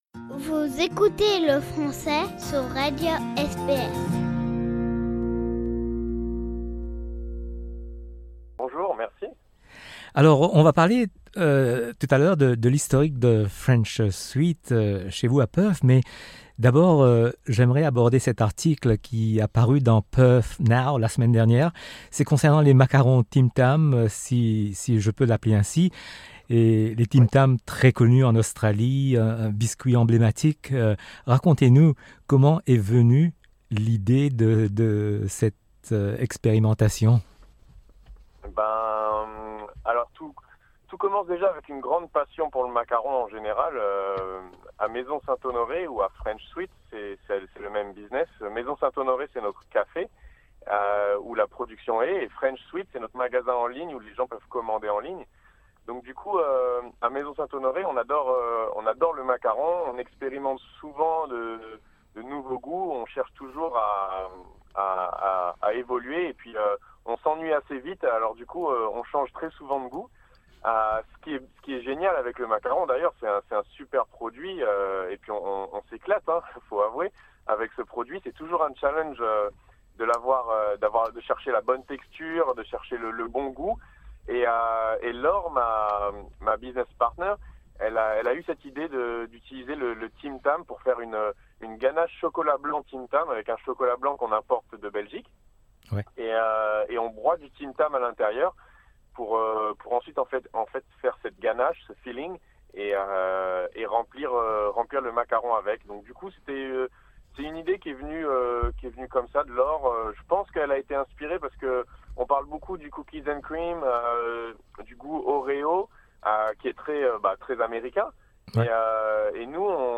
Au téléphone avec nous